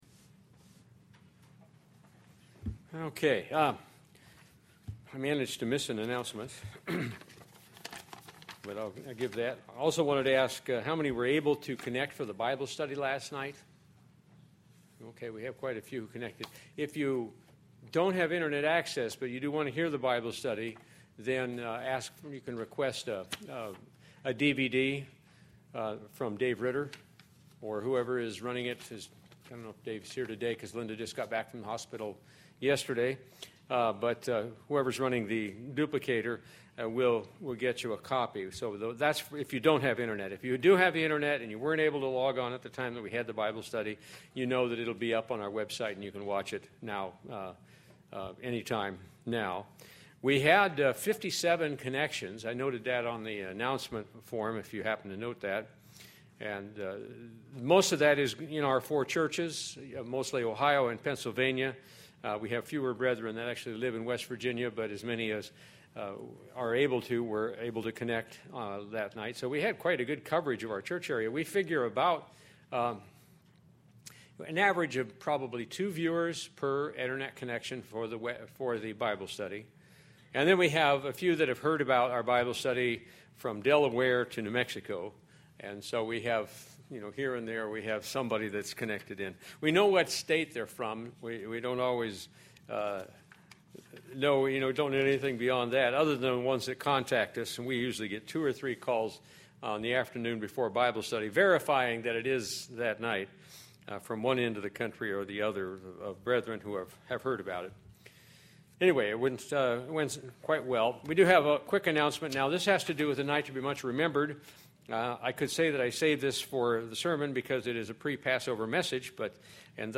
Given in North Canton, OH
Jesus Christ Passover sermon Studying the bible?